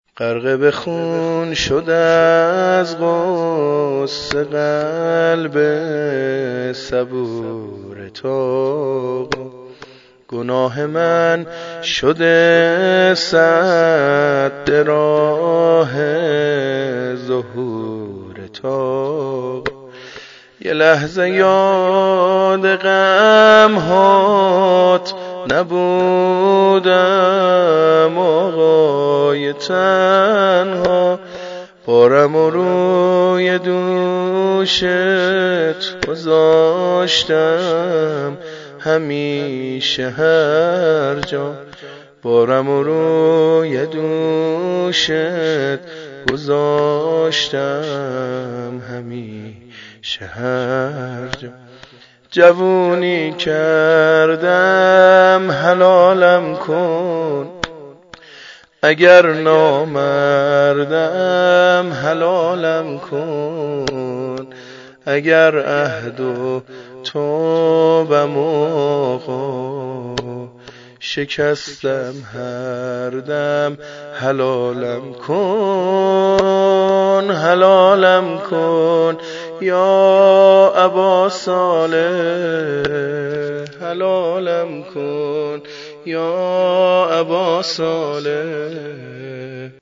مناجات با امام زمان (عج) -( غرق به خون شد از غصه قلب صبورت آقا )